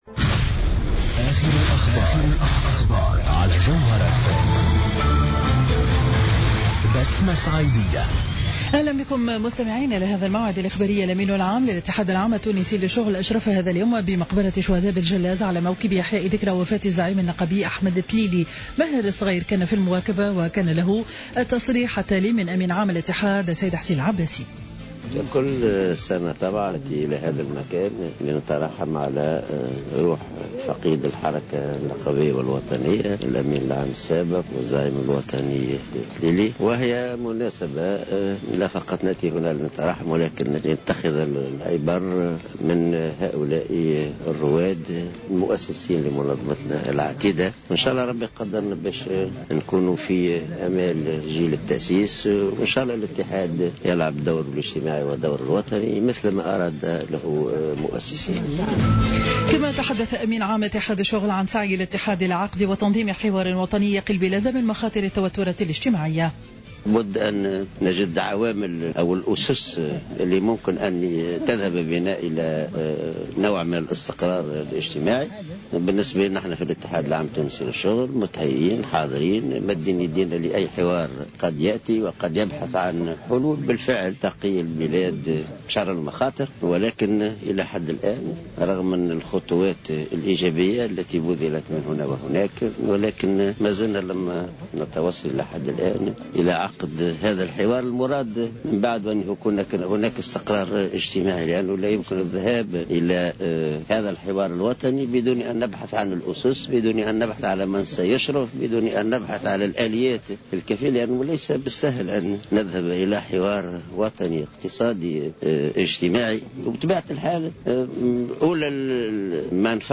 نشرة أخبار منتصف النهار ليوم الخميس 25 جوان 2015